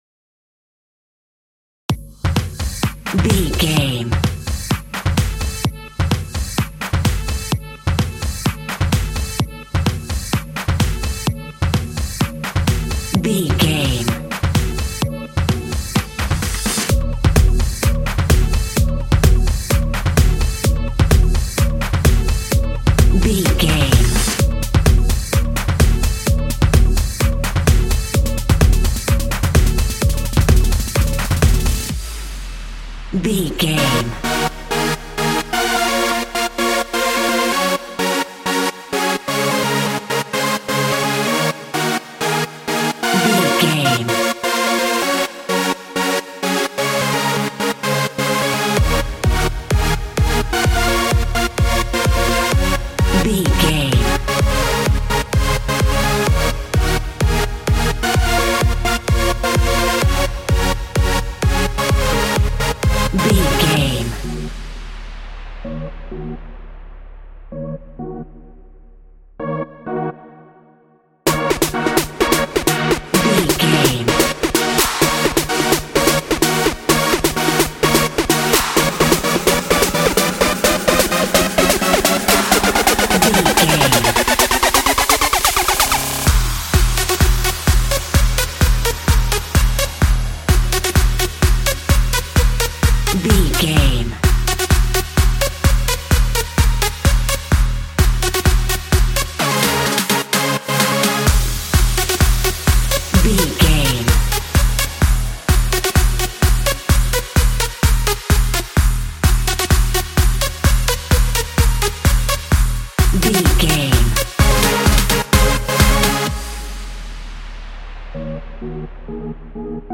Ionian/Major
F#
groovy
futuristic
uplifting
synthesiser
drum machine
acid house
electronic
uptempo
synth leads
synth bass